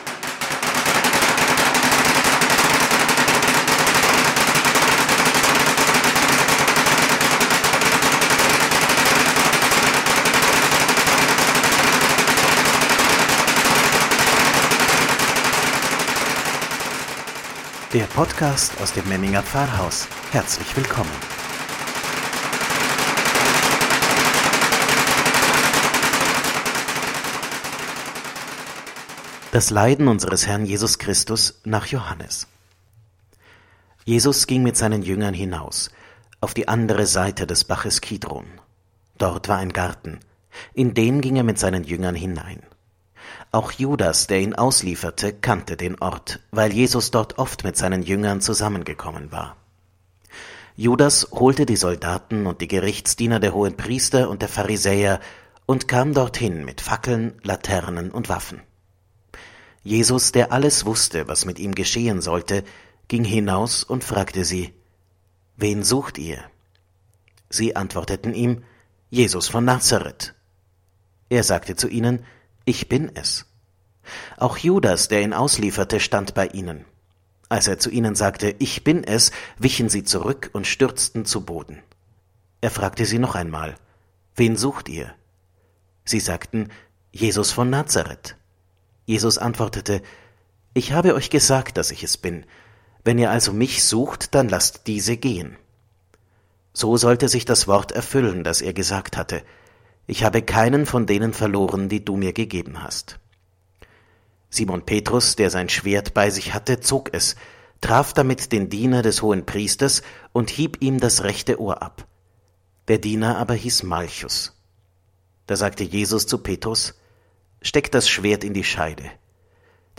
„Wort zum Sonntag“ aus dem Memminger Pfarrhaus – Karfreitag 2021